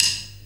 D2 SDRIM04-R.wav